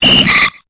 Cri de Poissirène dans Pokémon Épée et Bouclier.